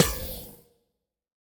Minecraft Version Minecraft Version snapshot Latest Release | Latest Snapshot snapshot / assets / minecraft / sounds / block / trial_spawner / place1.ogg Compare With Compare With Latest Release | Latest Snapshot